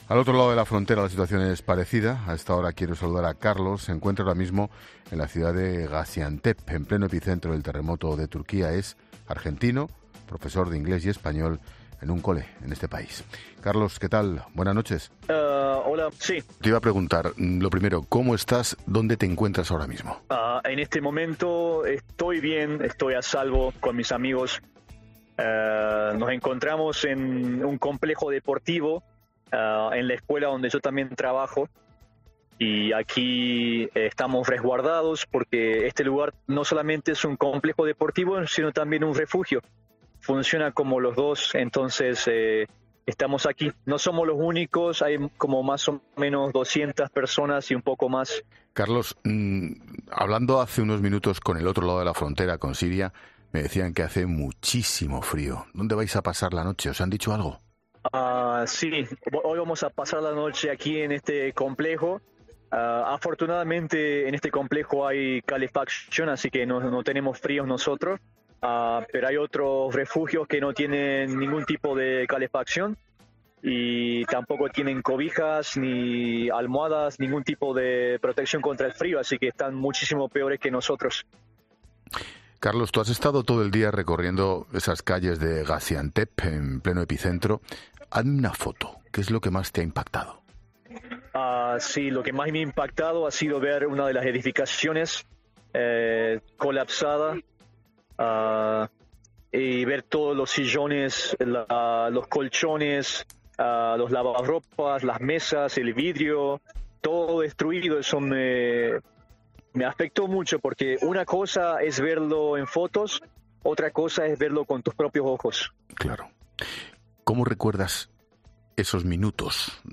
Un profesor de origen argentino atiende a Ángel Expósito y La Linterna horas después de un seísmo que se ha cobrado más de 1.500 vidas